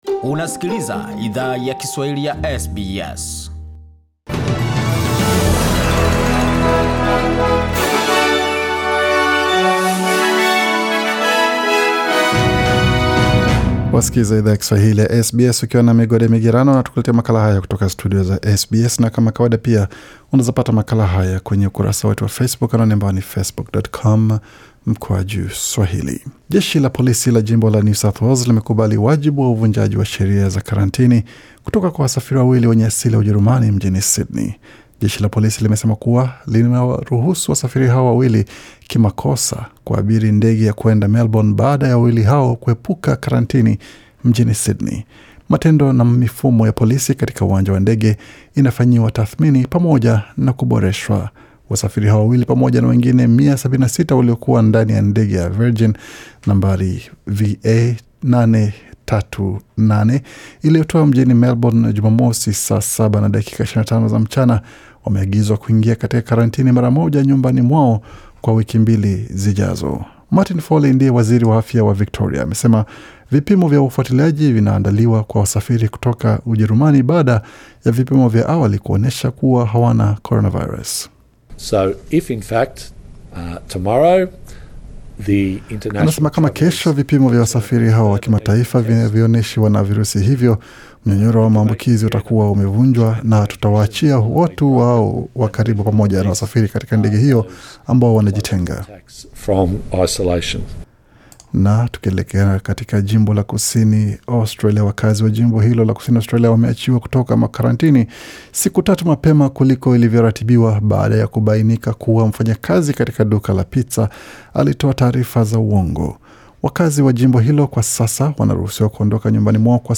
Taarifa ya habari 6 Disemba 2020